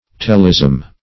Search Result for " telesm" : The Collaborative International Dictionary of English v.0.48: Telesm \Tel"esm\, n. [Ar. tilism.